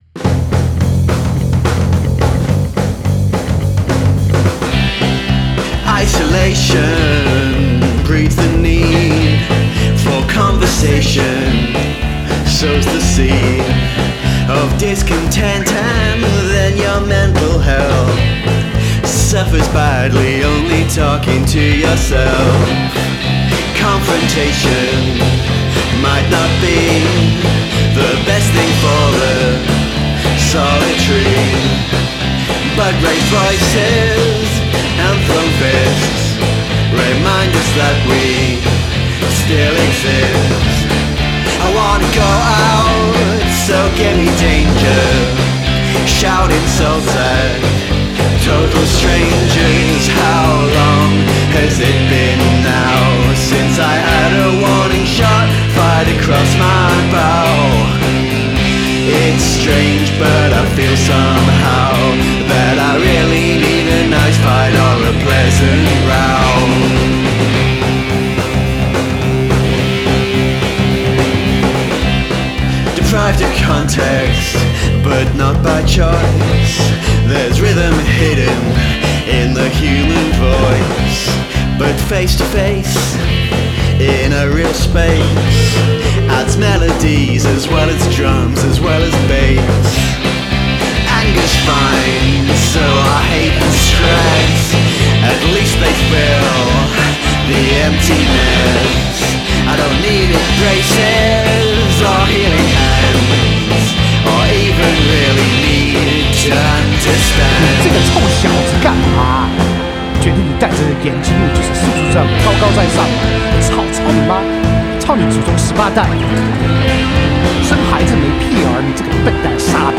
Must include a guest singing or speaking in another language